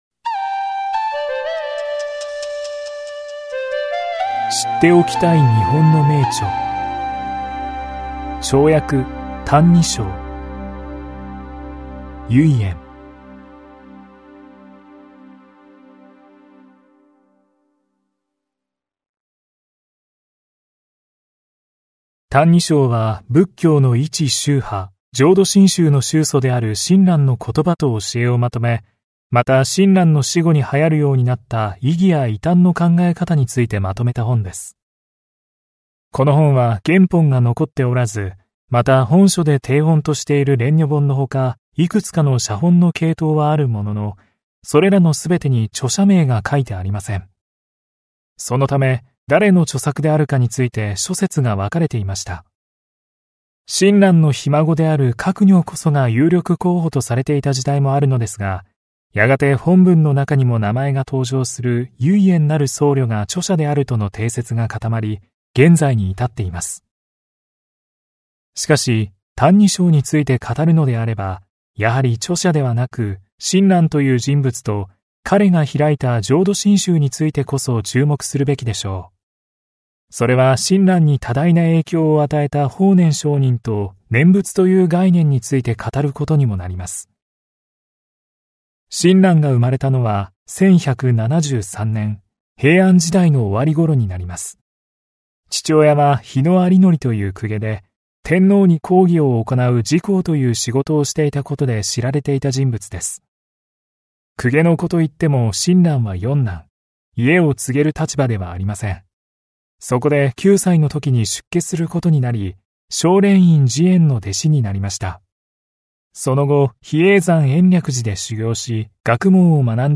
[オーディオブック] 超訳 歎異抄